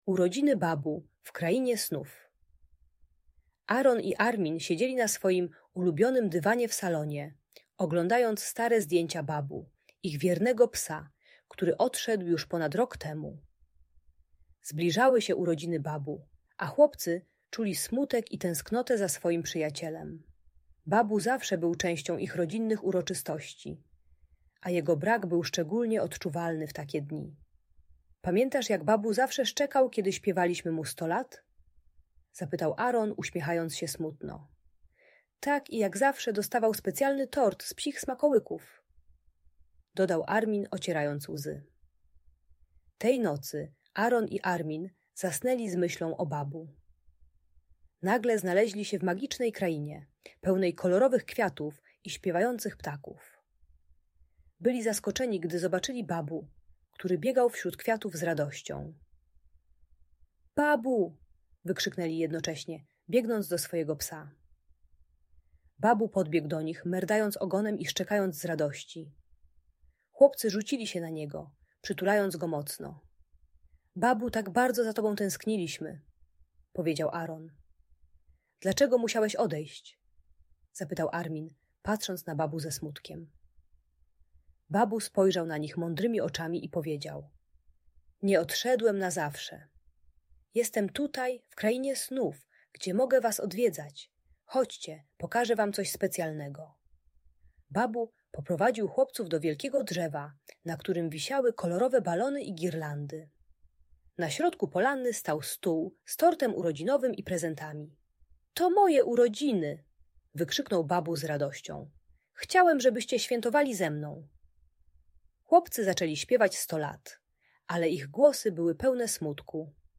Urodziny Babu w Krainie Snów - Lęk wycofanie | Audiobajka